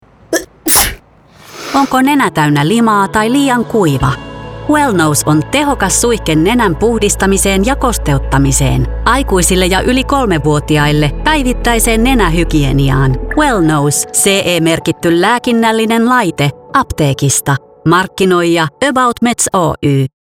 aboutmeds-wellnose-radiomainos-20-sek.mp3